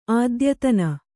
♪ ādyatana